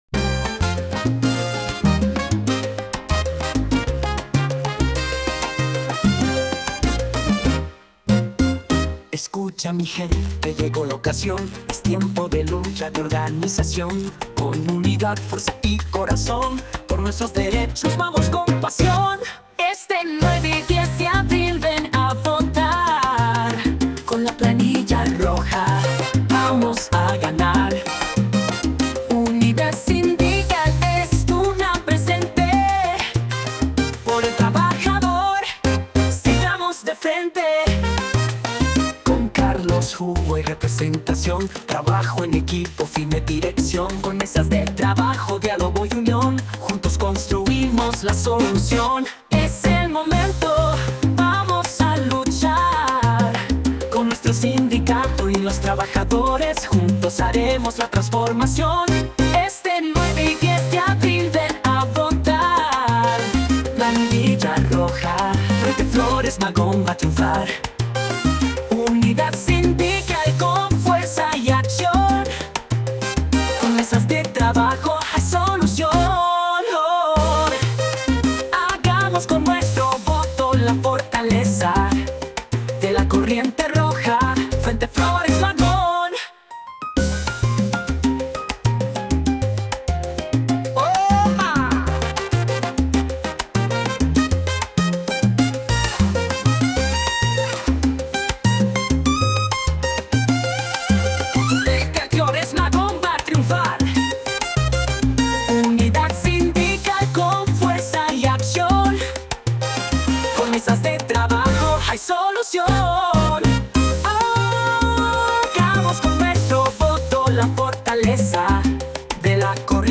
Género: Norteño